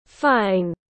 Trời khô ráo tiếng anh gọi là fine, phiên âm tiếng anh đọc là /faɪn/.